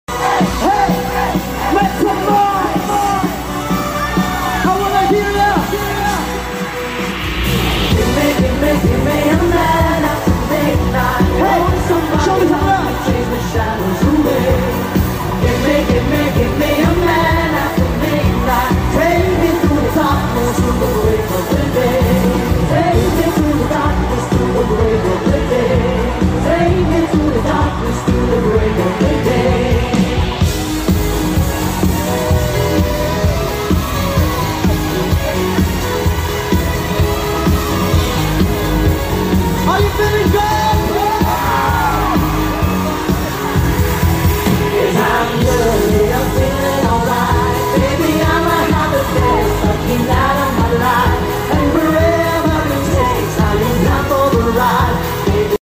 Concert at Manila